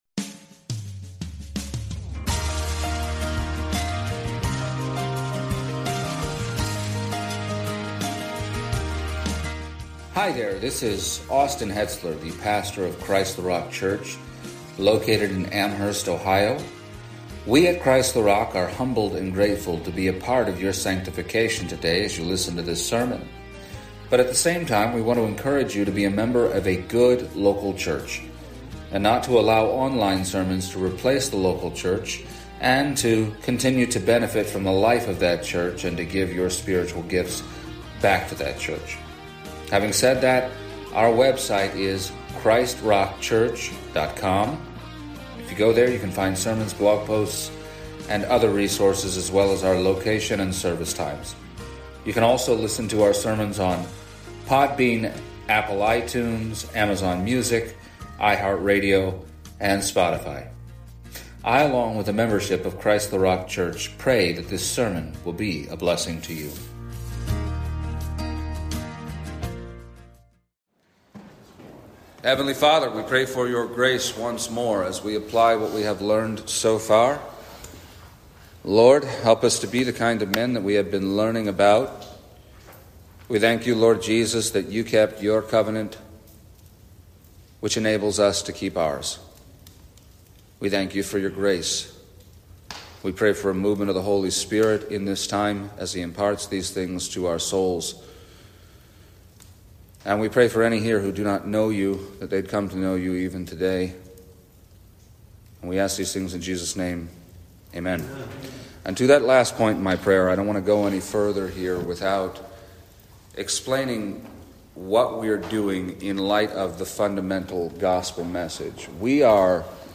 The third of three messages given at the 2025 CtRC Men’s Summit